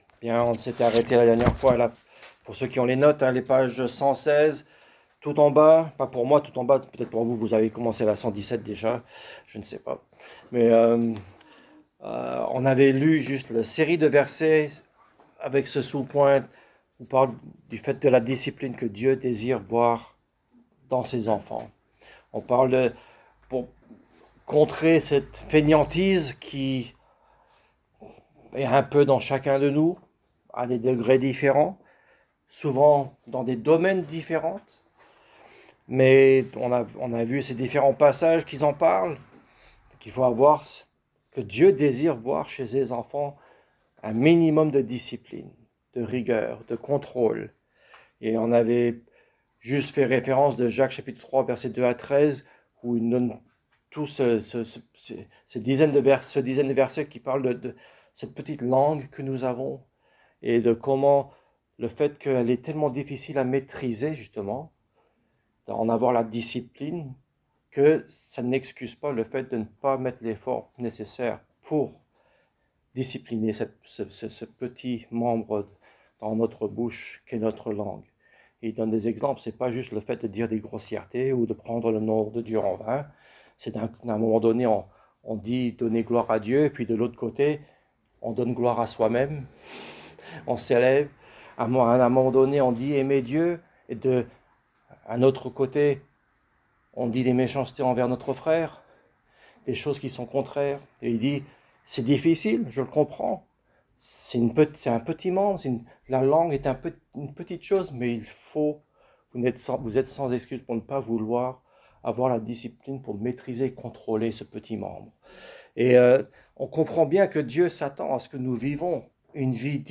Thème: Fainéantise Genre: Etude Biblique